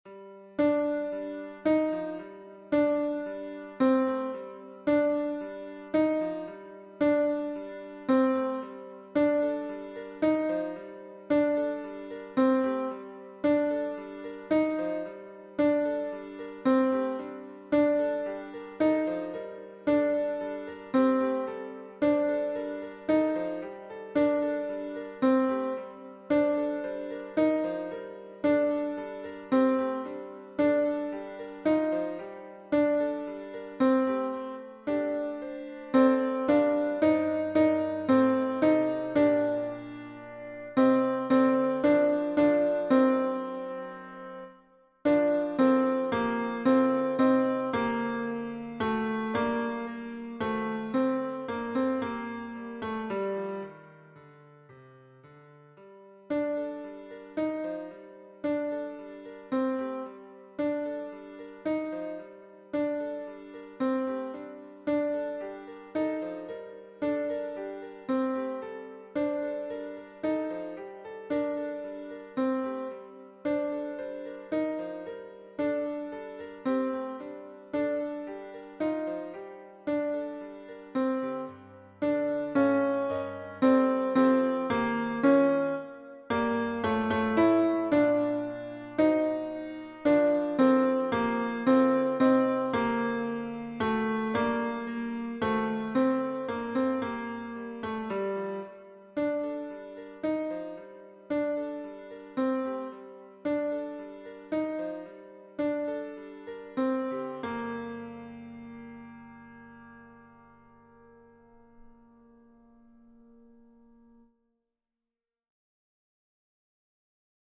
Tenor Piano